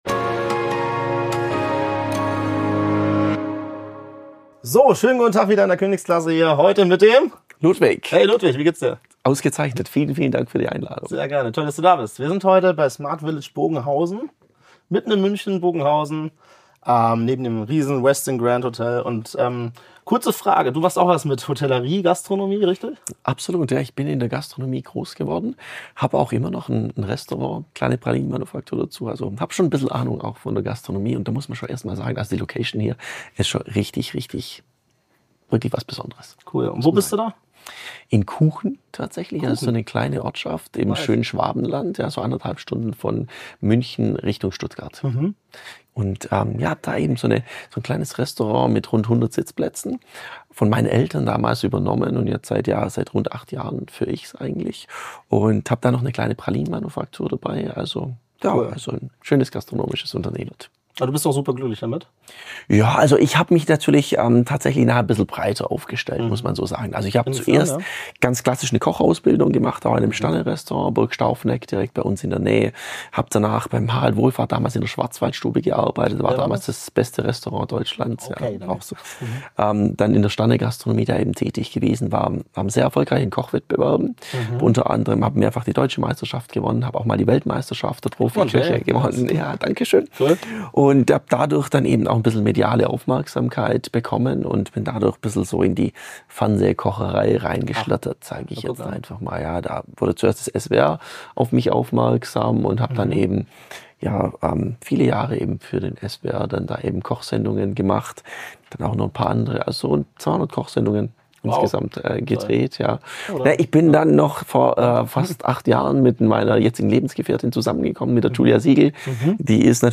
Beschreibung vor 11 Monaten Zusammenfassung Das Gespräch findet bei Smart Village Bogenhausen in München statt.